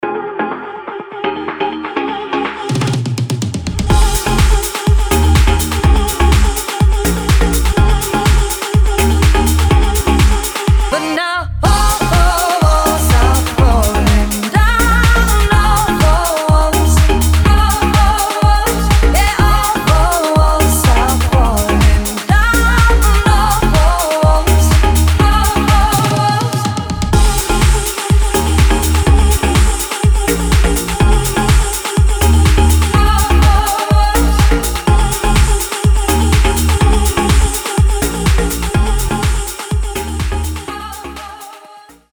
dance
Electronic
house